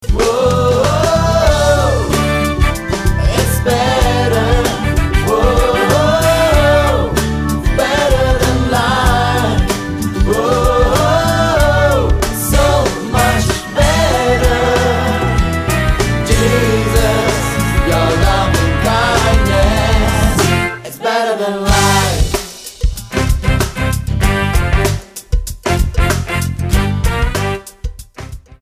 STYLE: Pop
This is a studio produced album